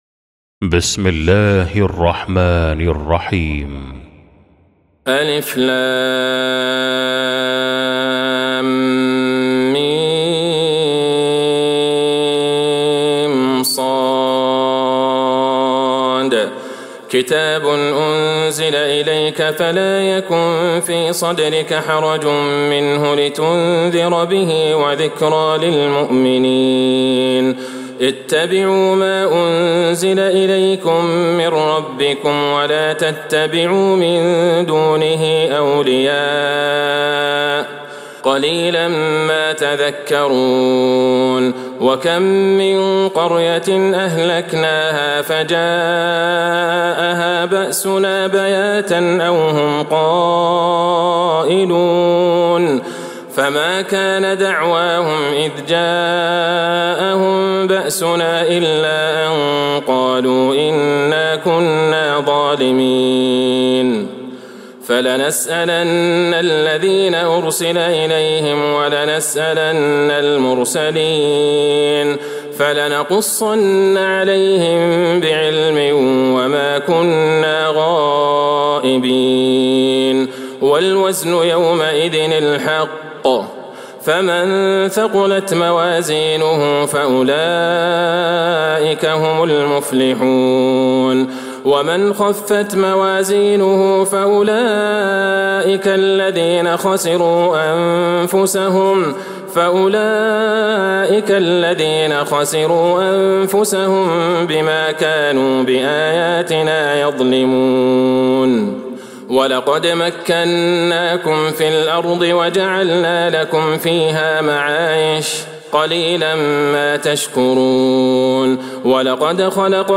سورة الأعراف | Surah Al-A'raf > مصحف تراويح الحرم النبوي عام 1446هـ > المصحف - تلاوات الحرمين